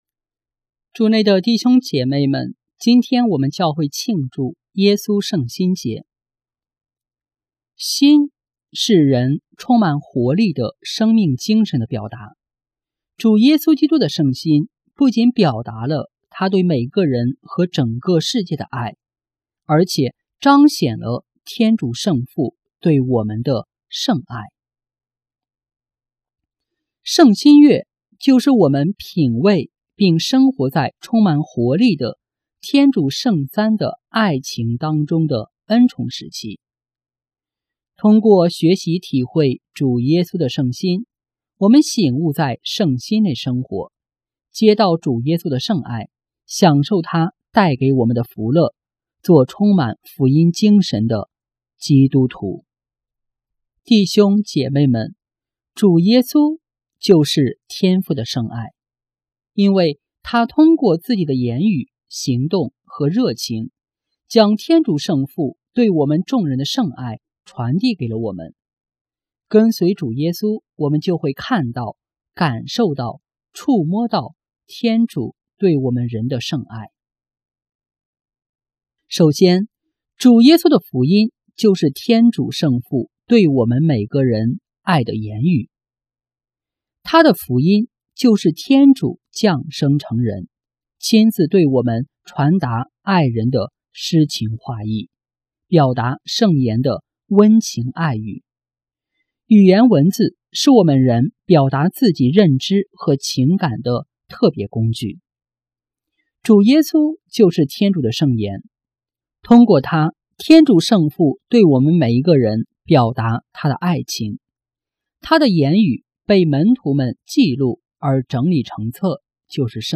【主日证道】| 体认圣心享主爱（耶稣圣心节）